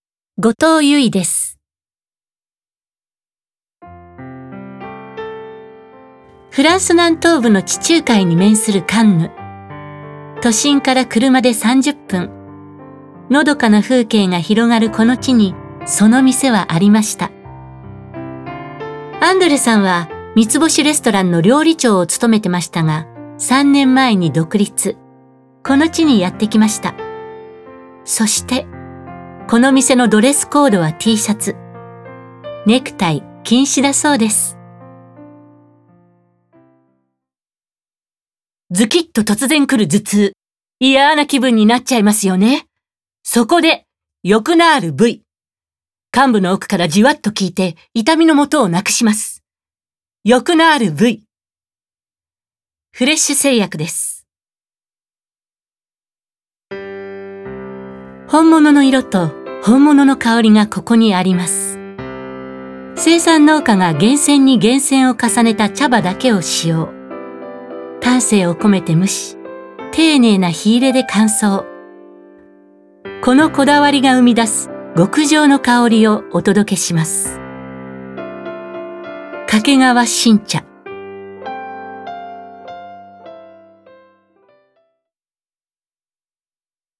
• 女優
VOICE SAMPLE